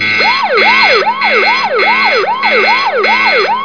bankalarm.mp3